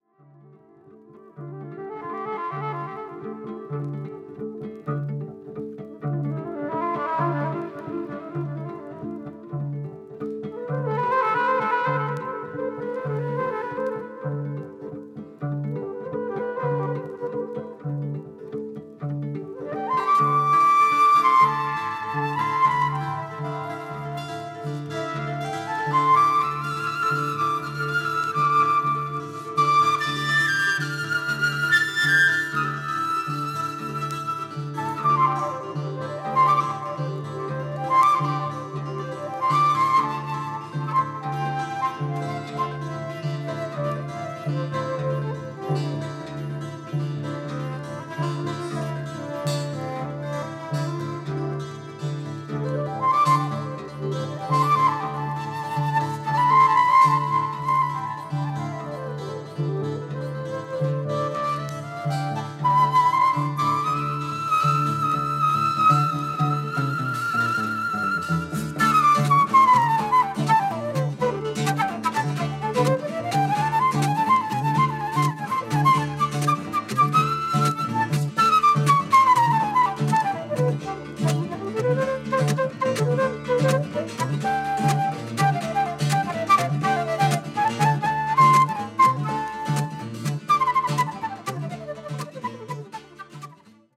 フォーク・ワールドミュージックも取り入れたアプローチが素敵です。